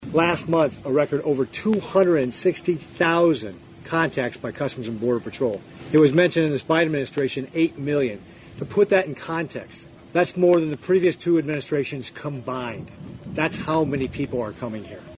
RICKETTS SAYS RECORD NUMBERS OF IMMIGRANTS CONTINUE TO CROSS OUR SOUTHERN BORDER WITHOUT ANY RESTRICTIONS: